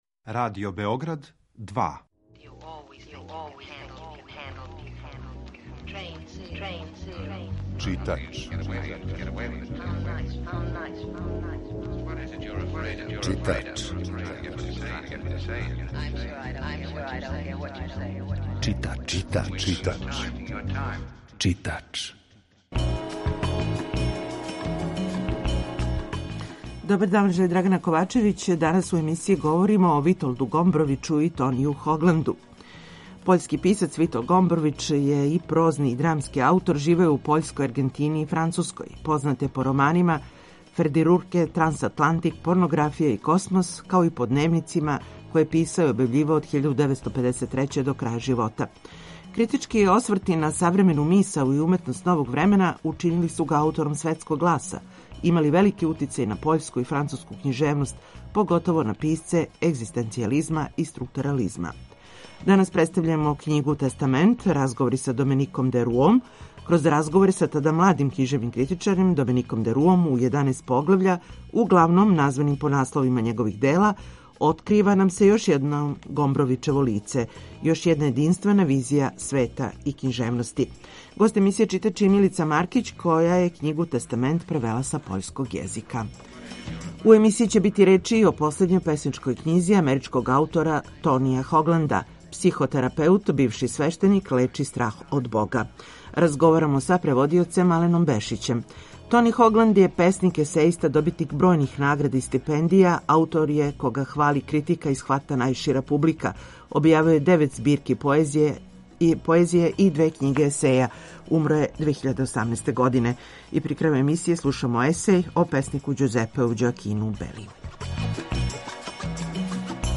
Емисија је колажног типа, али је њена основна концепција – прича о светској књижевности